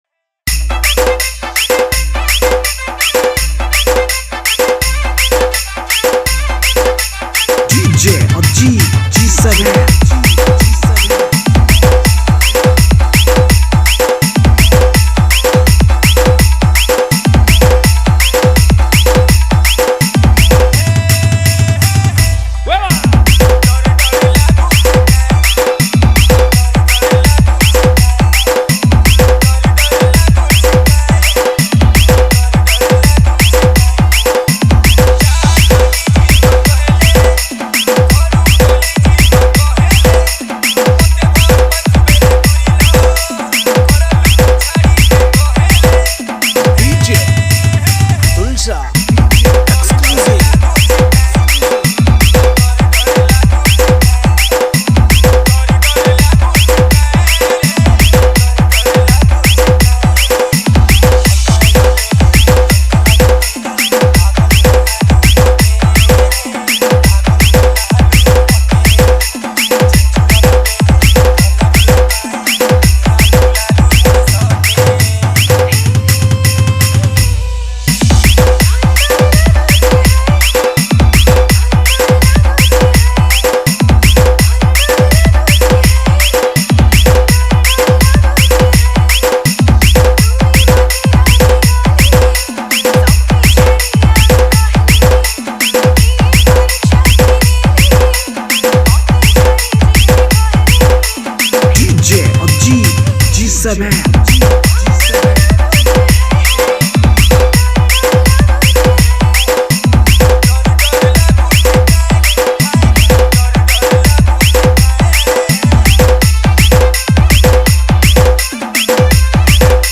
Category:  Sambalpuri Dj Song 2025